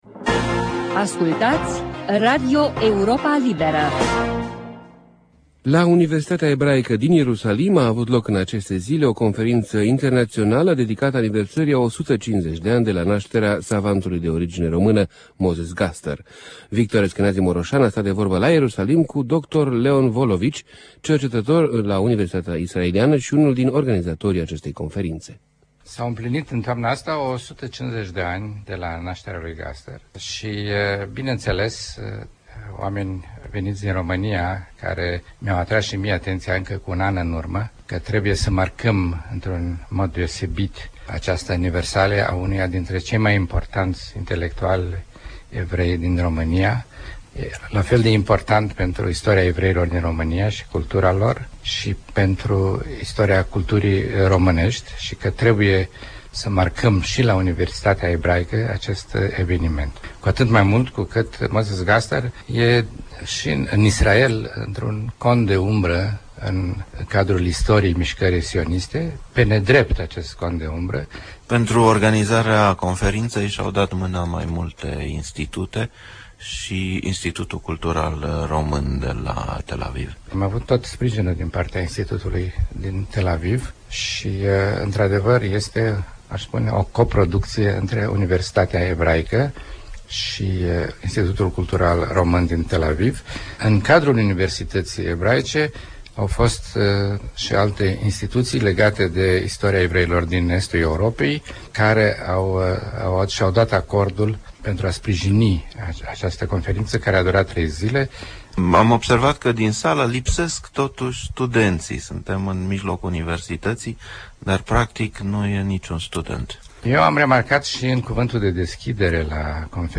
De vorbă cu profesorul universitar